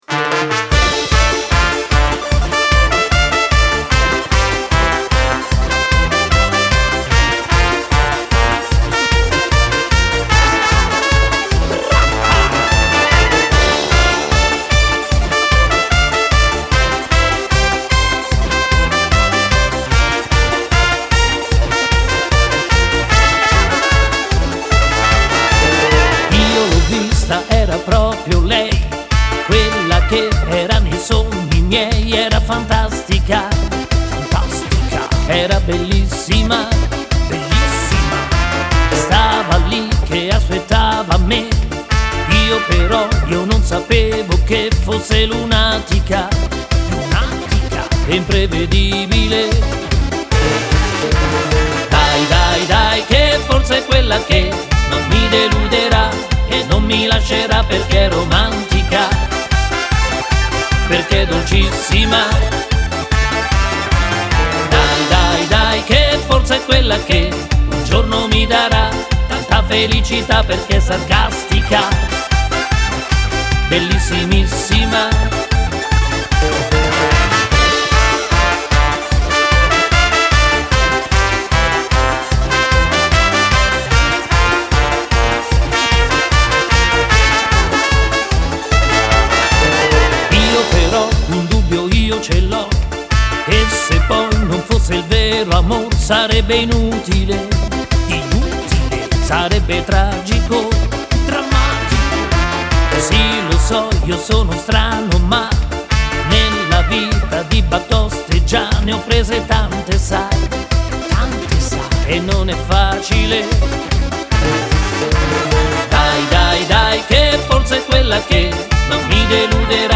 Merengue